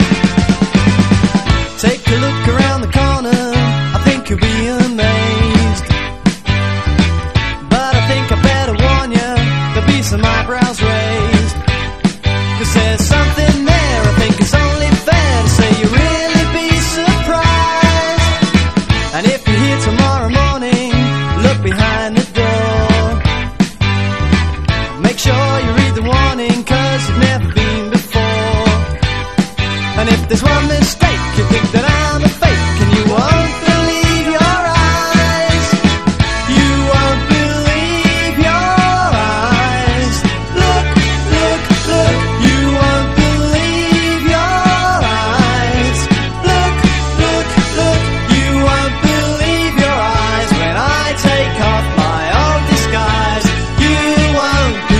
ROCK / PUNK / 70'S/POWER POP/MOD / NEO MOD (UK)